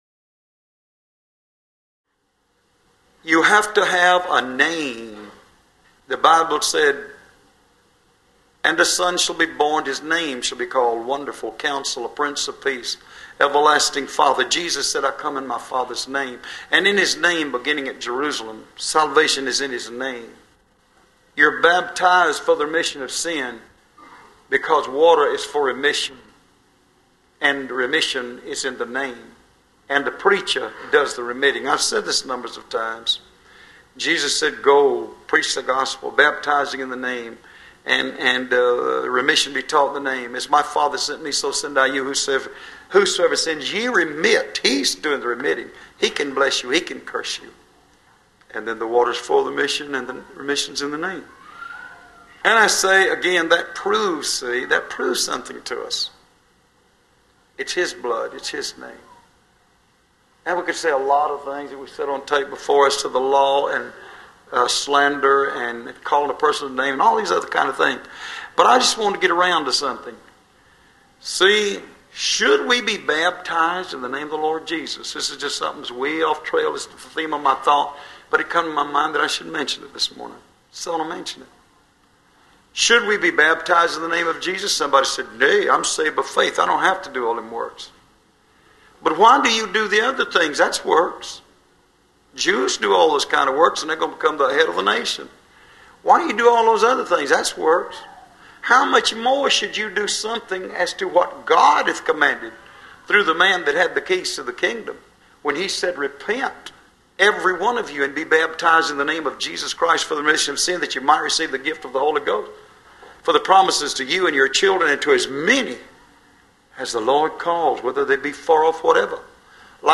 Sermons Starting With ‘O’